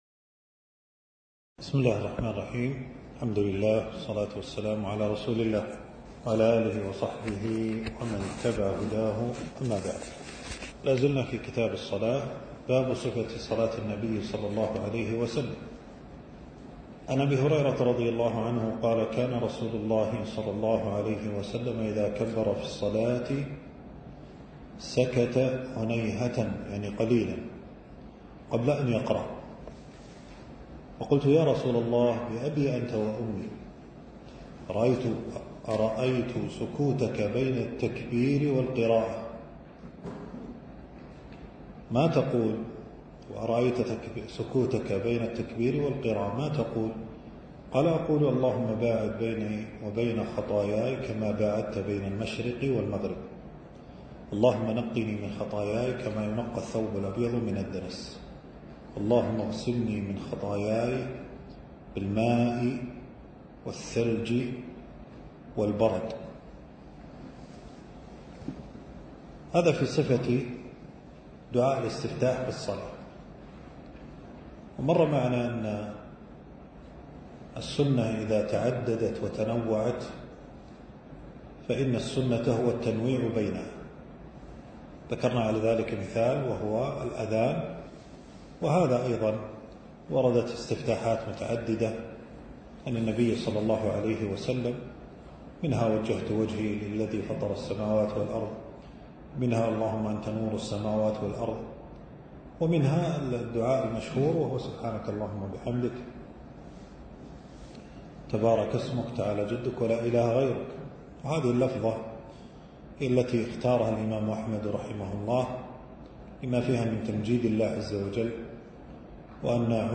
المكان: درس ألقاه في 3 جمادى الثاني 1447هـ في مبنى التدريب بوزارة الشؤون الإسلامية.